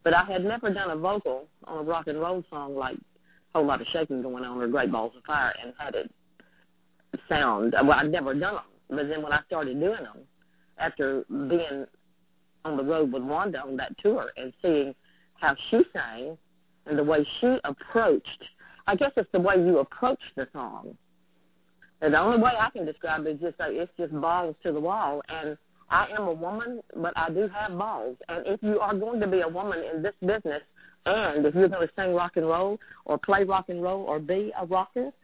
Interview Highlights: Linda Gail Lewis
Linda-Gail-Lewis-Interview_clip.mp3